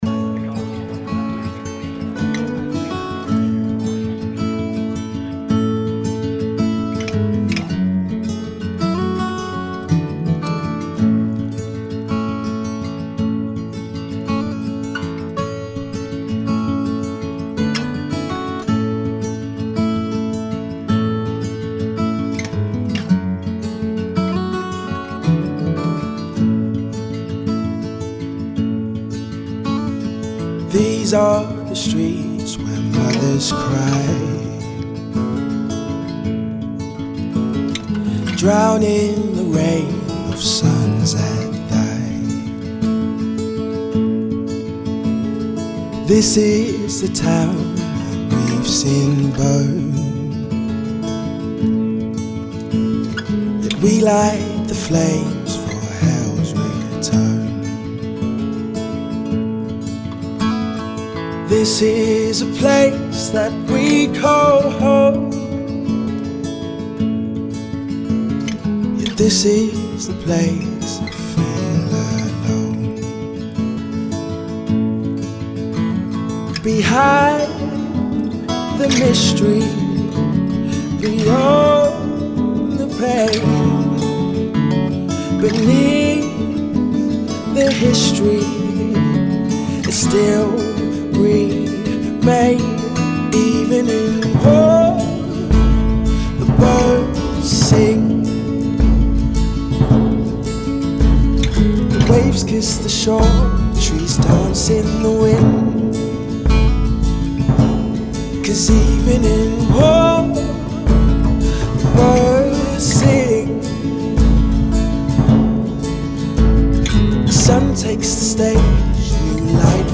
voice has very strong emotive abilities